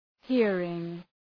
Προφορά
{‘hırıŋ}